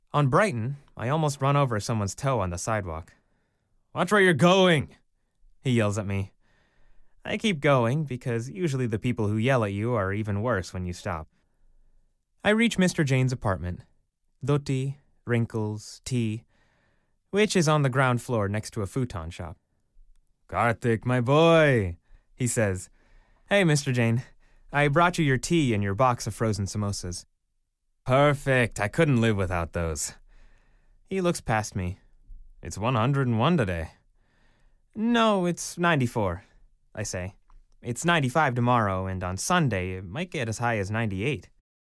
Whether it's animation, video games, commercials, or audiobooks, I've got your back in bringing your creative endeavors to life, recorded remotely from my home studio.
Audiobook - Karthik Delivers